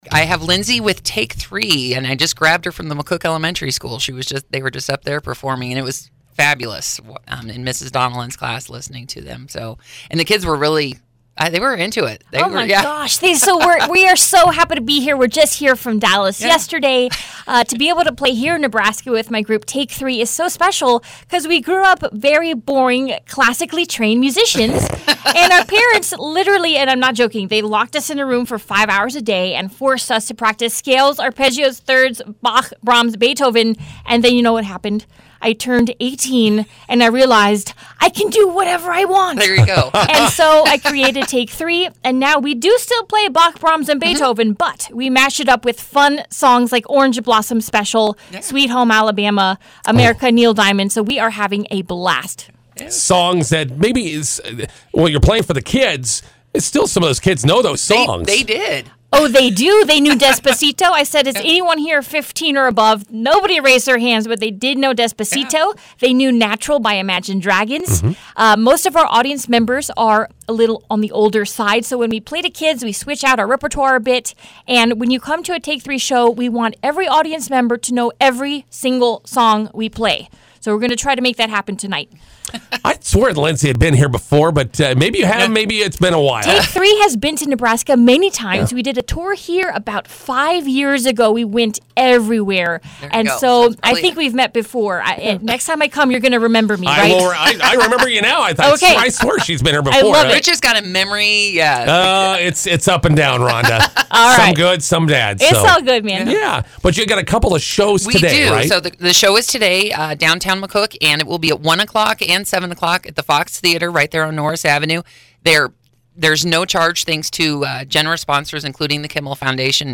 INTERVIEW: Take 3 performing today and tonight at the Fox Theatre.